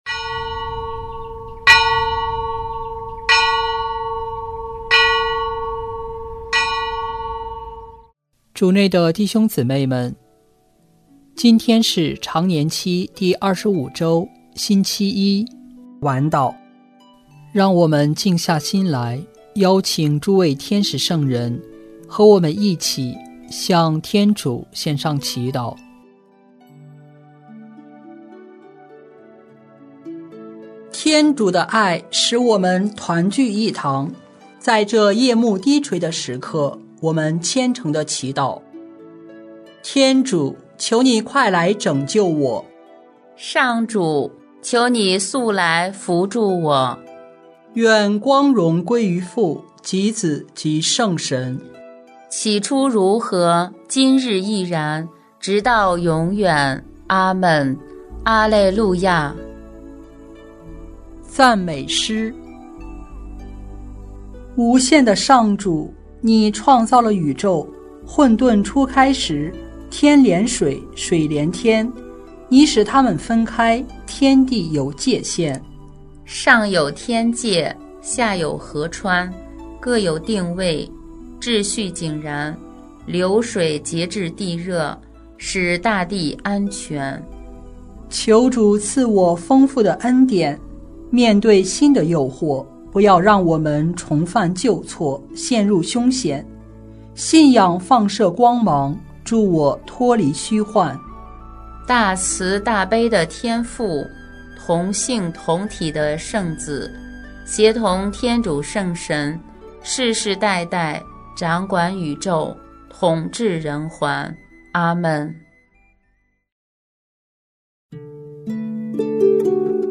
圣咏吟唱 圣咏 10 上主是义人的依靠 “慕义如饥似渴的人是有福的，因为他们要得饱饫。”